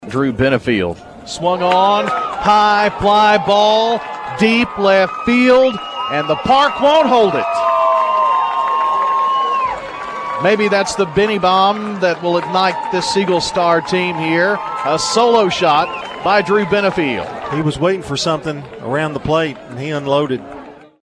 with the call on State Farm Prep Sports.